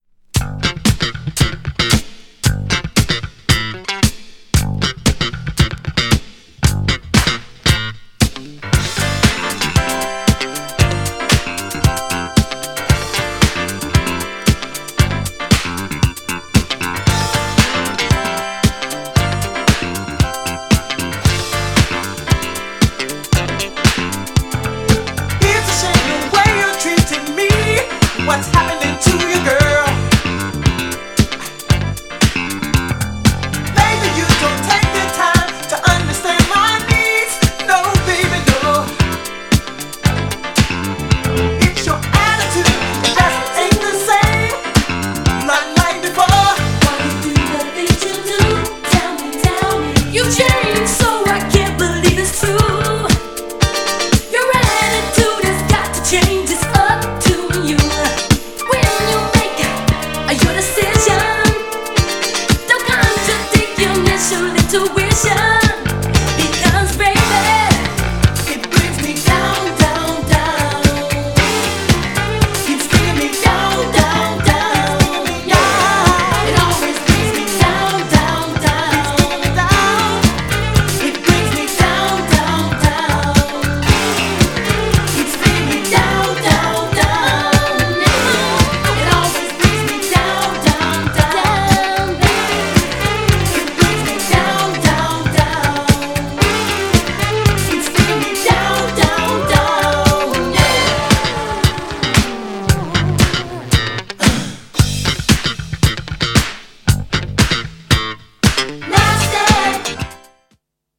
相変わらずの骨太なFUNKだけどメロディはかなりキャッチー!! ベースもゴリゴリいってます!!
B面もかなりスタイリッシュな80's FUNKでこちらも最高!!
GENRE Dance Classic
BPM 111〜115BPM